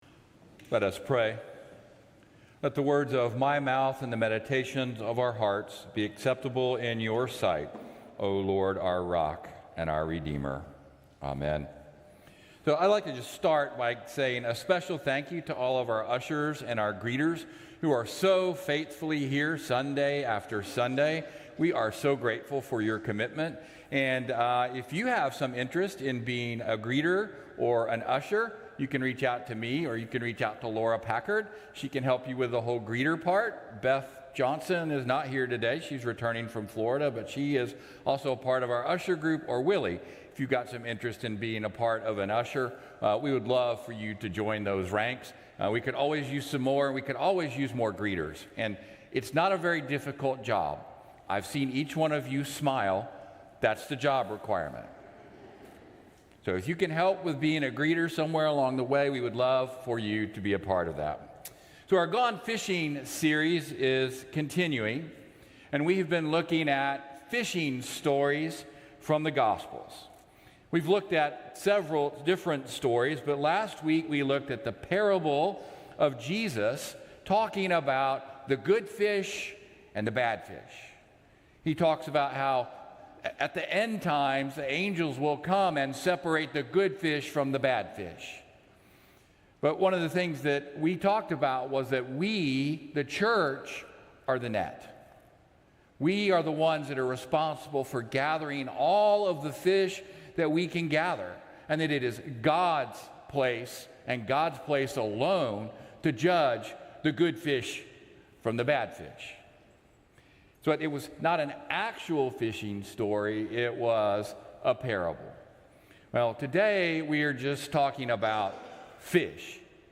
Traditional-Service-—-Jul.-23.mp3